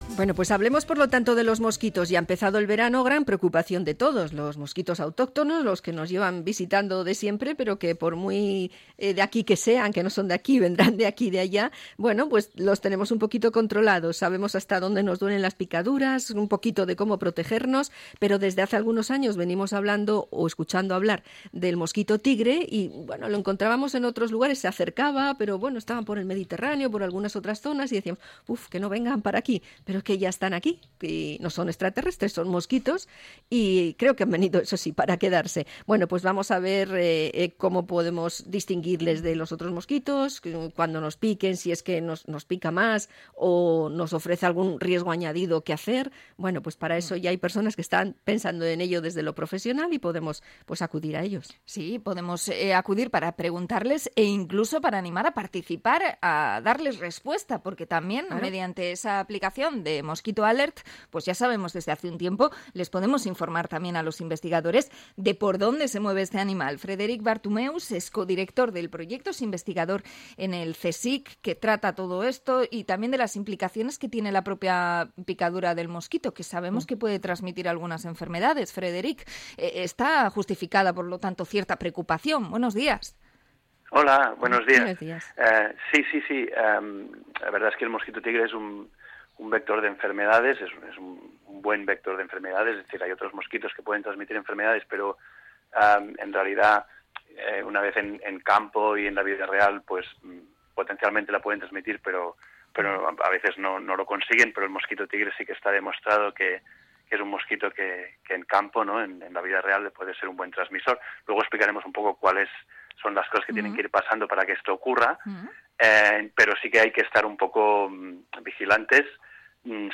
Entrevista a investigador sobre el mosquito tigre